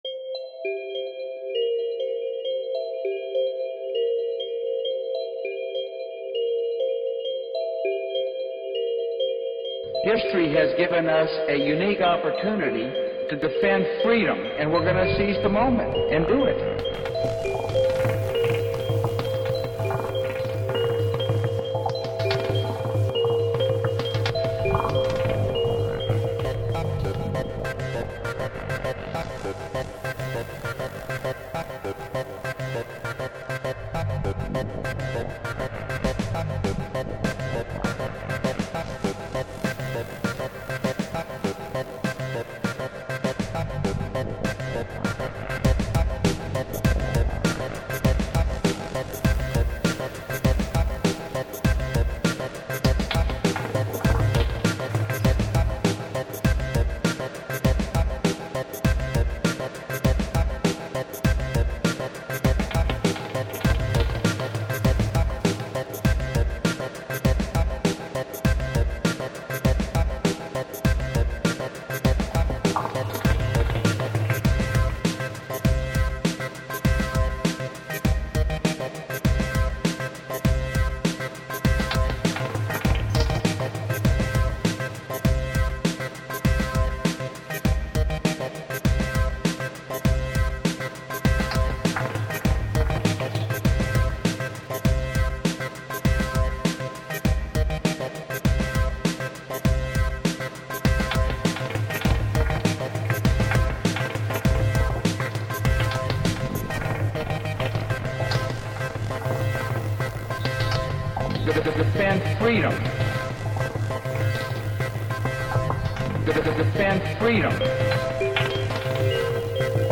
Creator: Cow Tse Tung Sound System
Chill rhythm.